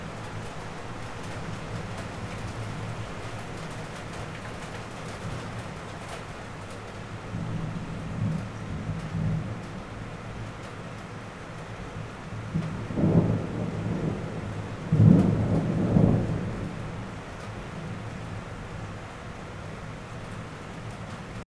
Index of /audio/samples/SFX/IRL Recorded/Rain - Thunder/
Rain 4.wav